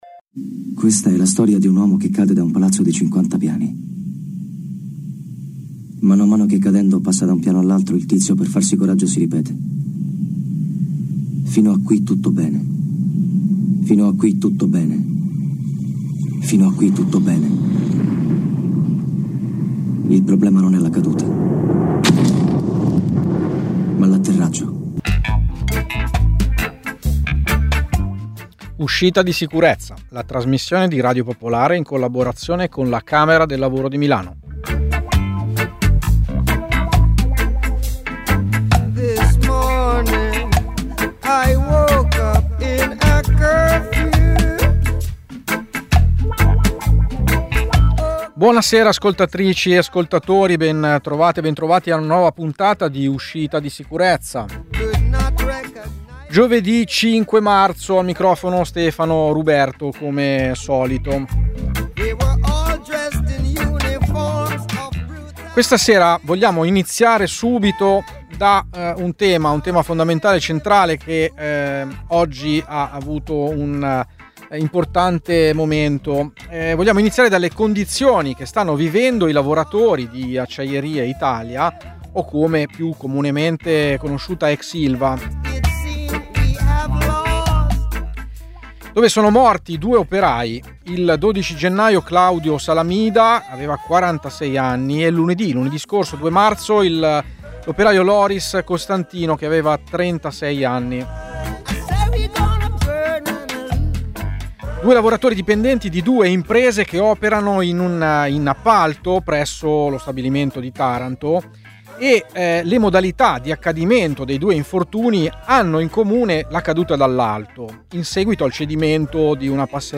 Fare quadrato un progetto che in Toscana ha consentito di emancipare dallo sfruttamento tanti lavoratori che hanno denunciato i loro caporali. La testimonianza diretta di un lavoratore migrante dal Pakistan